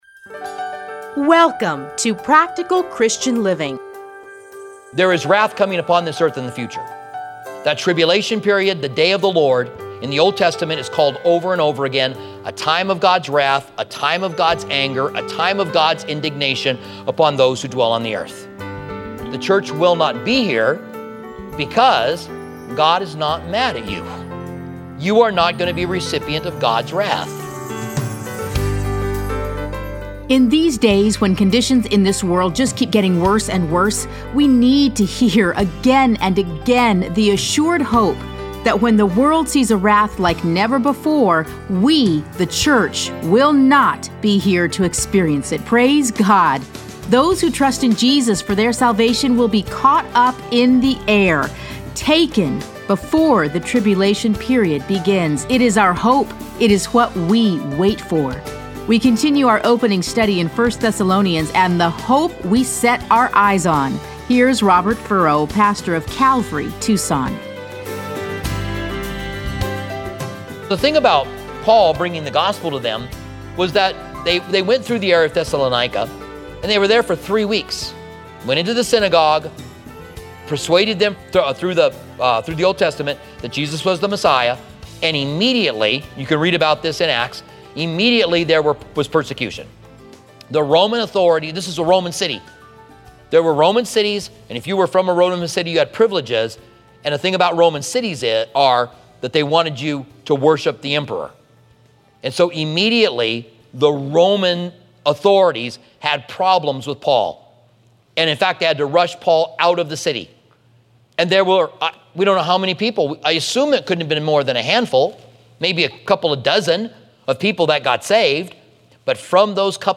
Listen to a teaching from 1 Thessalonians 1:1-10.